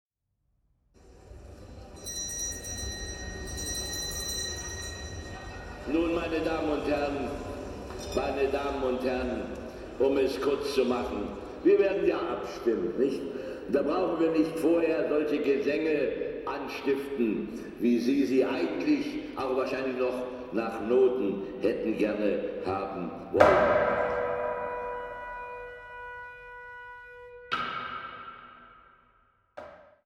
Neue Musik für Flöte und Orgel (II)
Flöte
Orgel